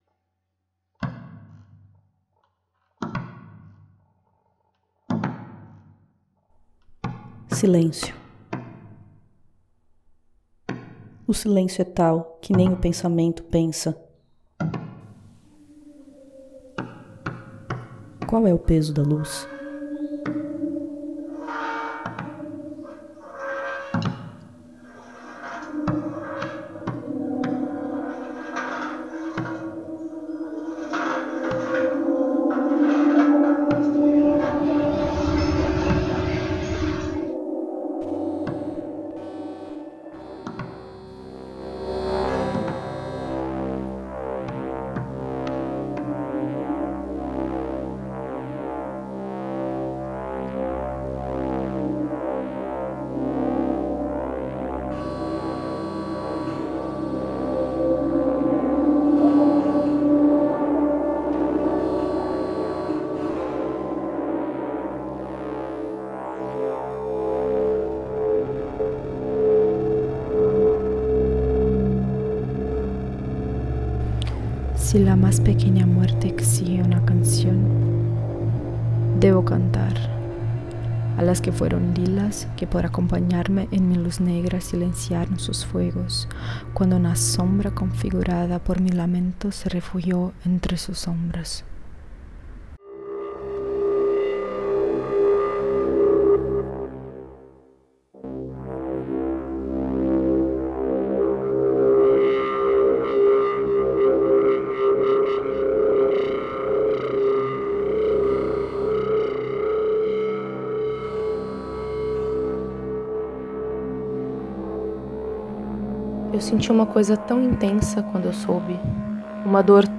Áudios e vozes
Sintetizadores e criação sonora